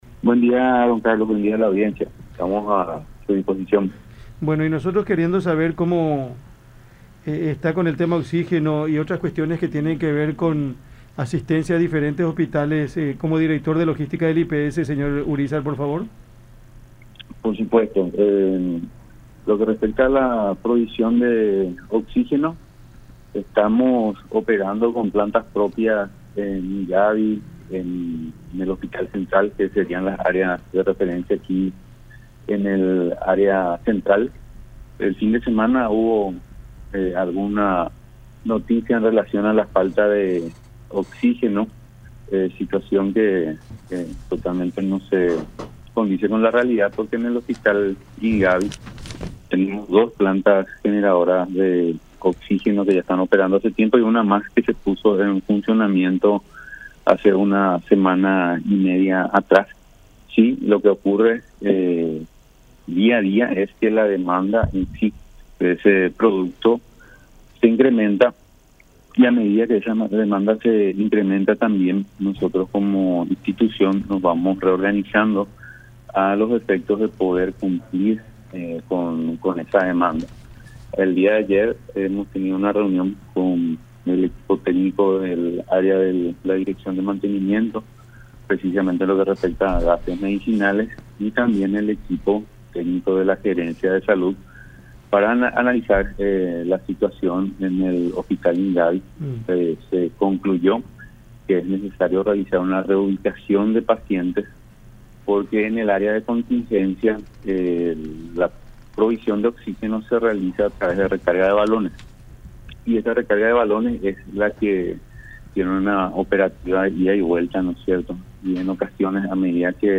en conversación con Cada Mañana a través de La Unión.